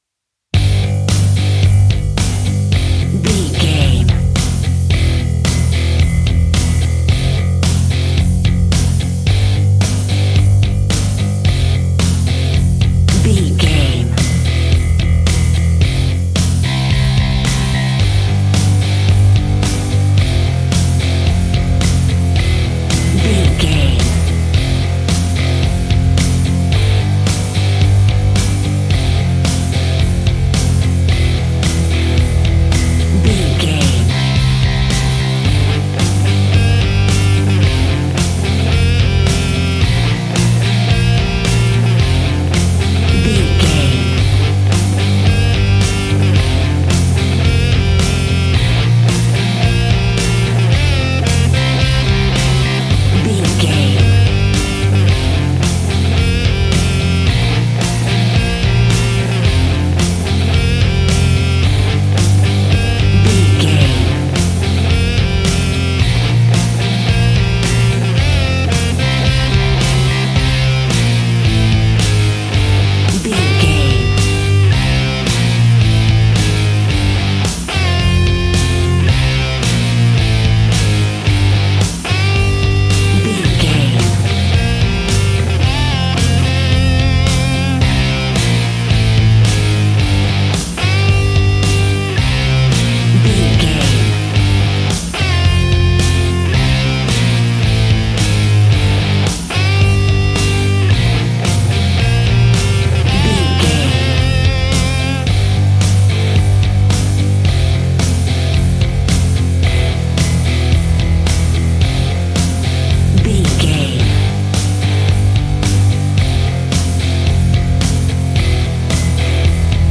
Epic / Action
Aeolian/Minor
hard rock
blues rock
distortion
rock instrumentals
rock guitars
Rock Bass
heavy drums
distorted guitars
hammond organ